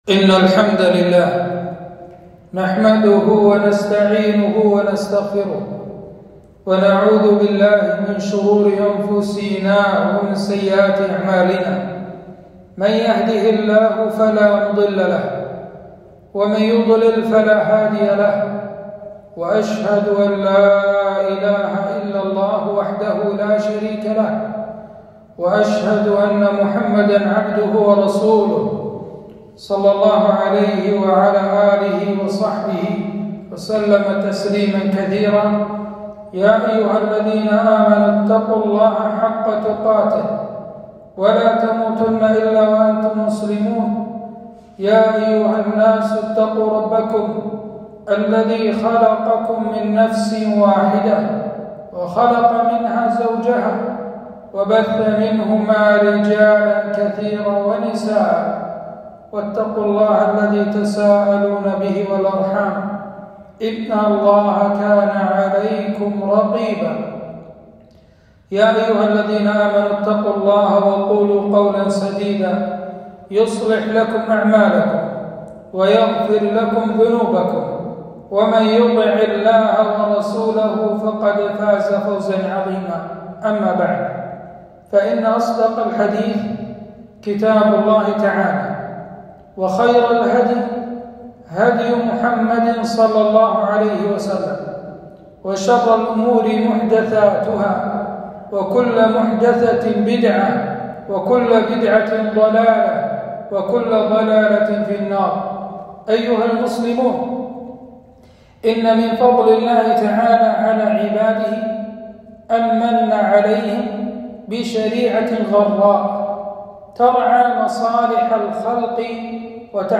خطبة - أحكام زكاة الفطر